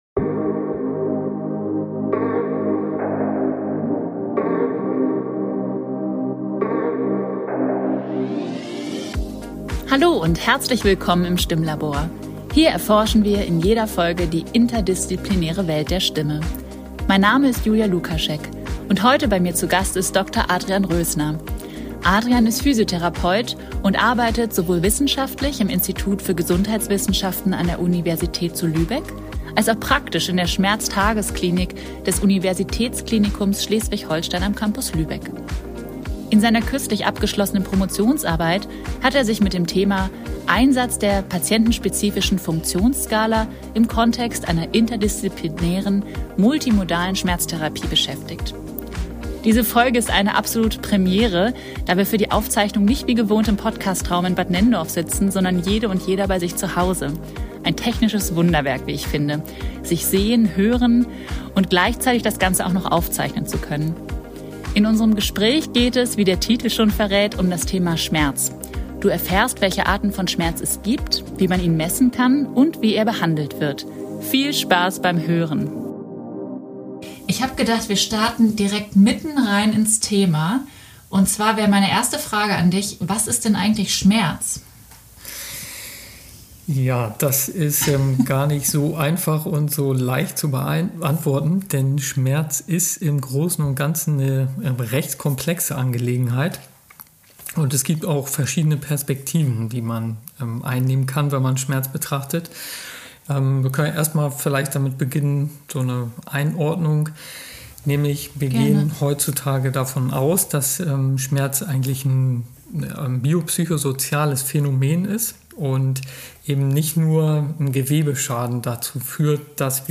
Diese Folge ist eine Premiere, da wir für die Aufzeichnung nicht wie gewohnt im Podcast Raum in Bad Nenndorf sitzen, sondern jedeR bei sich zu Hause.